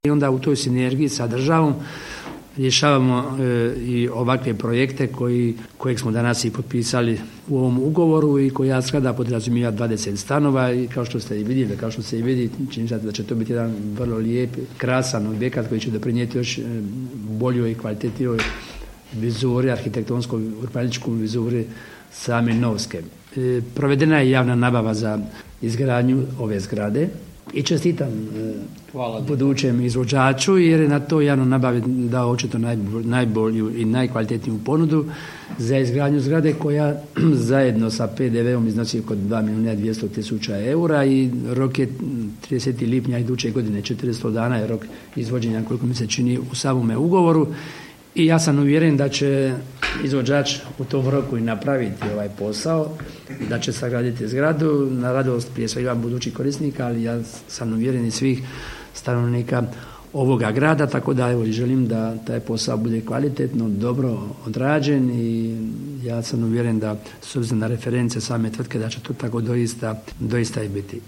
Resorni ministar Branko Bačić navodi kako je riječ o važnom ugovoru za daljnji razvoj grada Novske